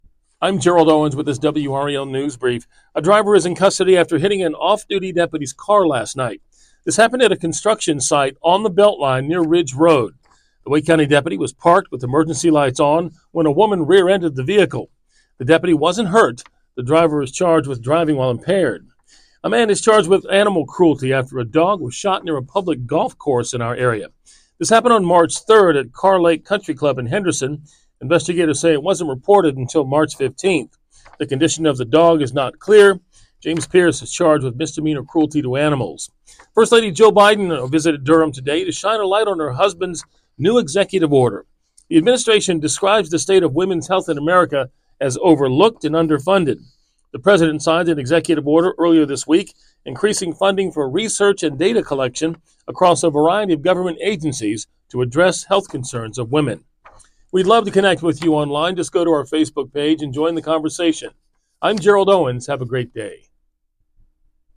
WRAL Newscasts